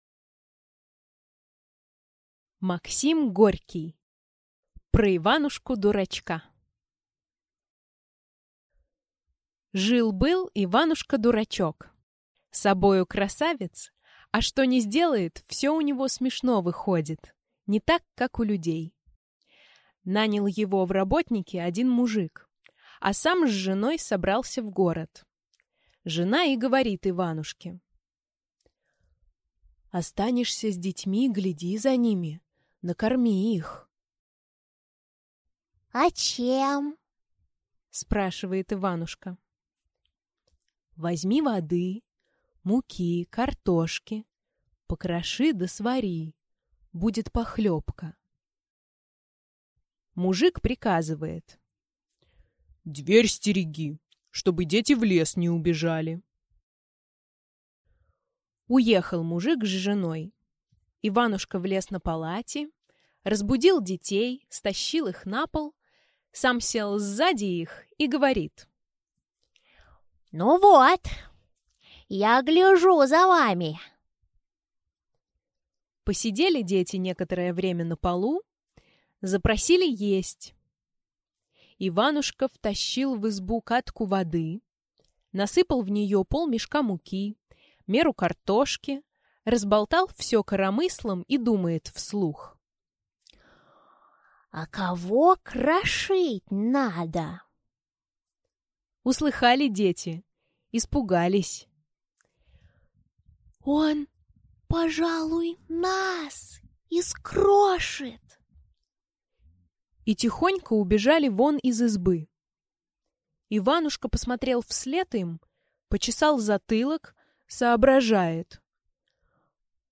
Аудиокнига Про Иванушку-дурачка | Библиотека аудиокниг
Aудиокнига Про Иванушку-дурачка Автор Максим Горький Читает аудиокнигу Дарья Мороз.